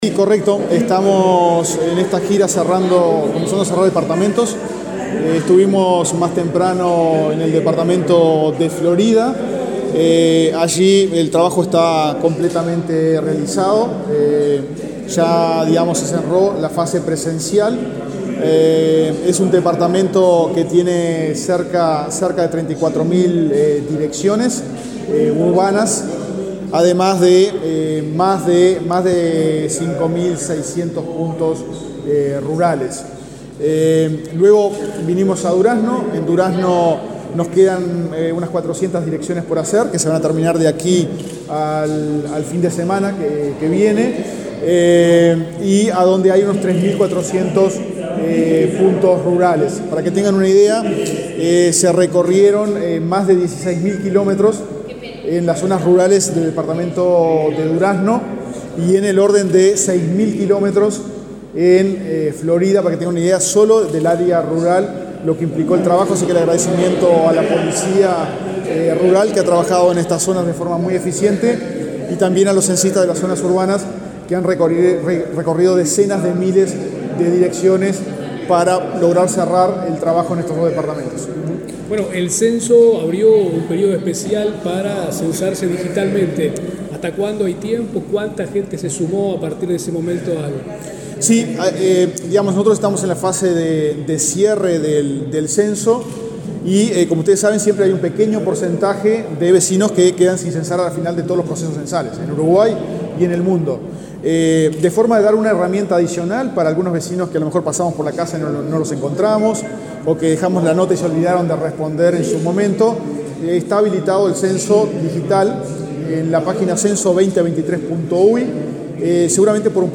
Declaraciones del director del INE, Diego Aboal, en Durazno